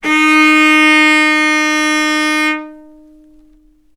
healing-soundscapes/Sound Banks/HSS_OP_Pack/Strings/cello/ord/vc-D#4-ff.AIF at cc6ab30615e60d4e43e538d957f445ea33b7fdfc
vc-D#4-ff.AIF